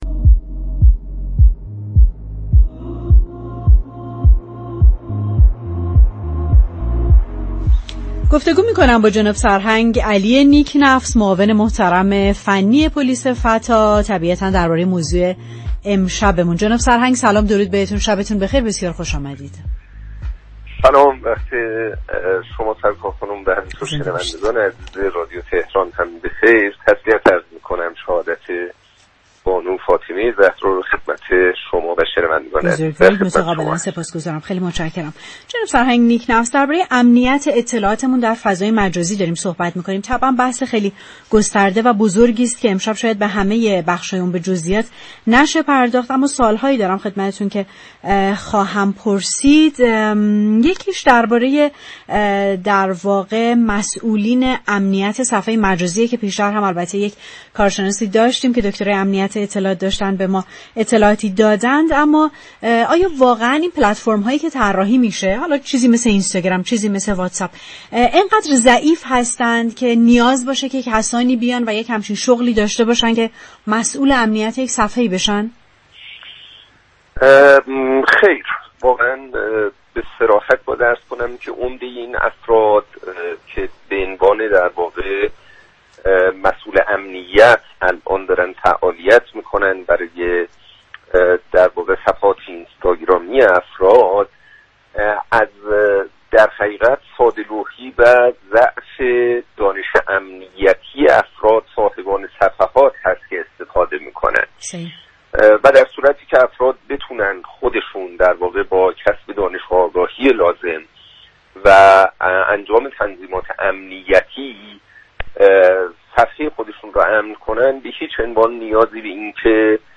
سرهنگ علی نیك نفس در گفتگو با برنامه پشت صحنه رادیو تهران درباره مسئولان امنیتی صفحات فضای مجازی گفت: به صراحت باید بگویم اگر صاحبان صفحات فضای مجازی بتوانند با كسب دانش و آگاهی لازم و انجام تنظیمات امنیتی صفحات خود را كنترل كنند نیازی به مسئولان امنیت صفحات فضای مجازی نخواهد بود.